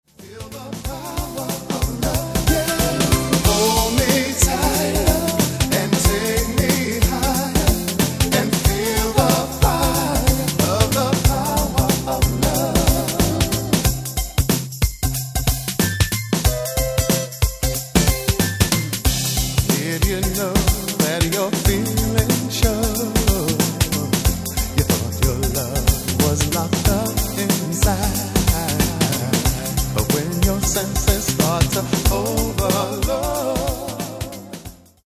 Genere:   RnB | Soul | Dance